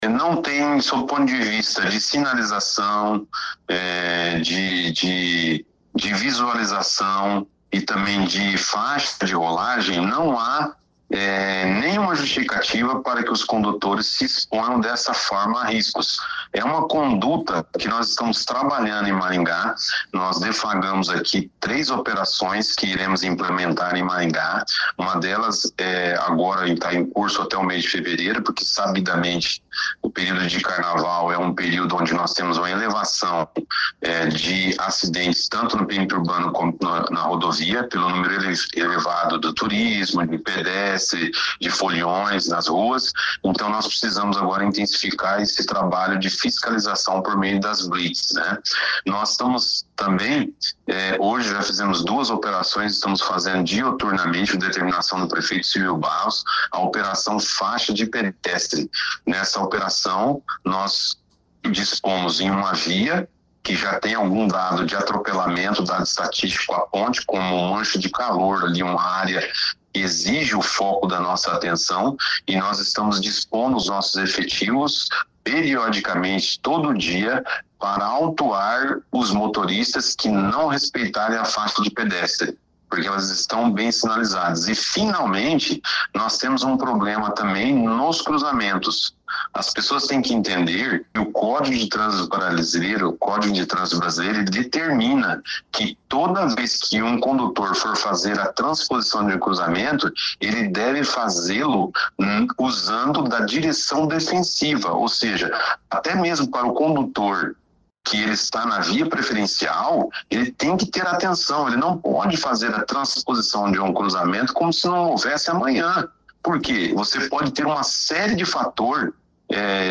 O secretário Luciano Brito disse que nos últimos três anos, houve registro de 23 acidentes nesse cruzamento.